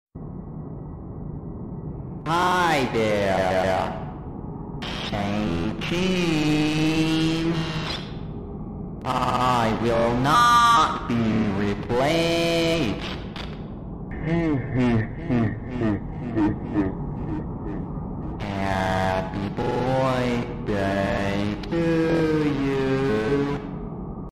Chuck E. Cheese’s Voice Lines sound effects free download